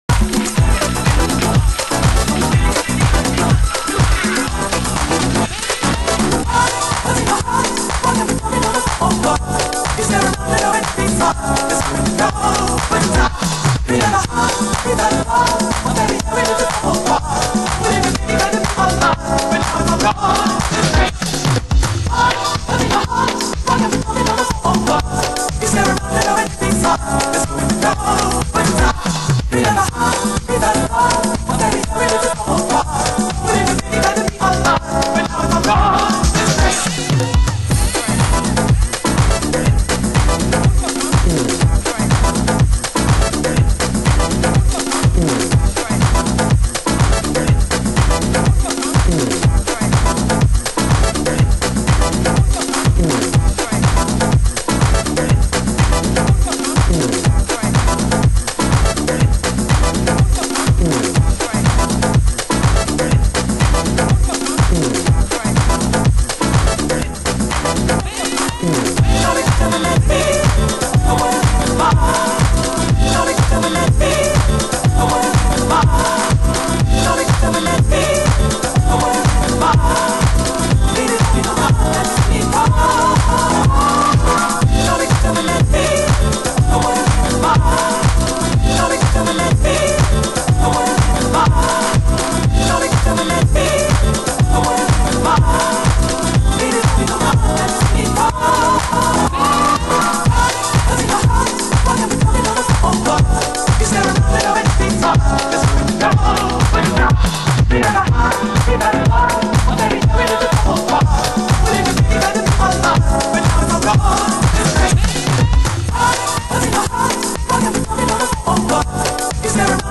ハウス専門店KENTRECORD（ケントレコード）